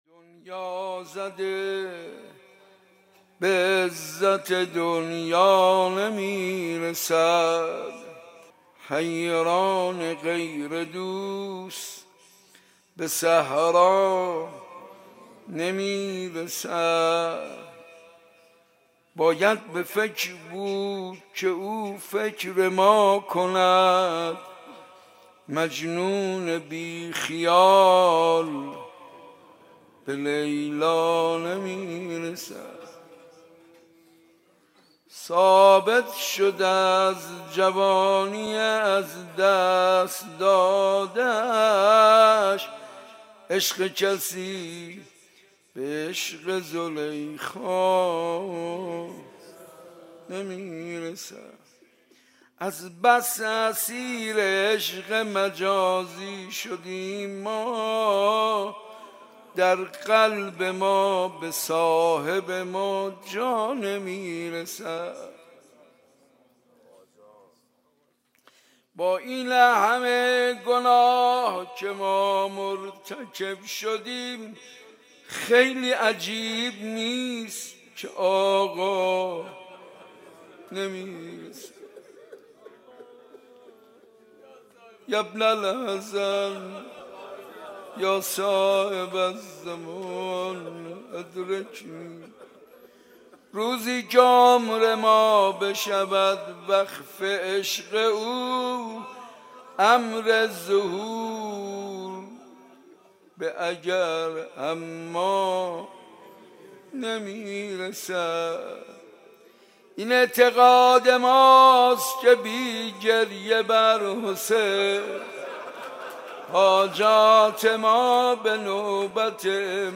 شعر مناجات :
زیارت عاشورای صنف لباس فروش ها